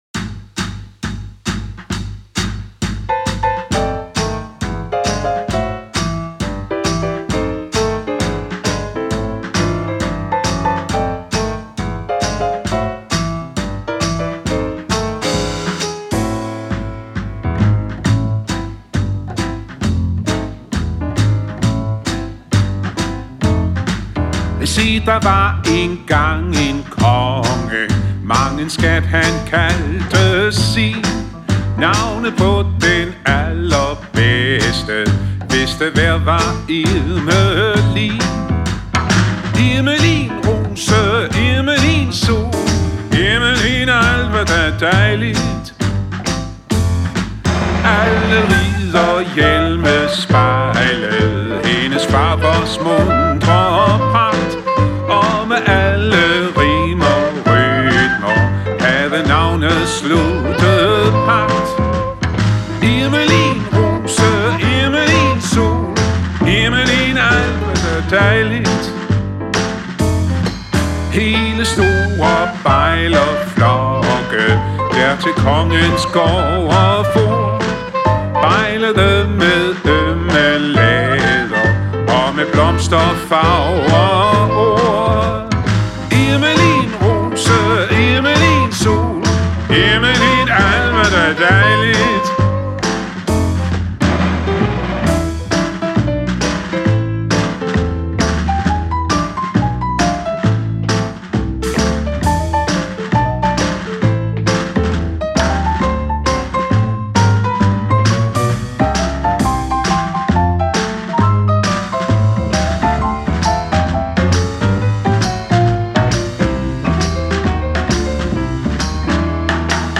så klangfulde baryton
Charleston-agtige
vokal, guitar
piano og arrangør
kontrabas
saxofoner